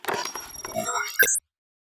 calibrate neutral.wav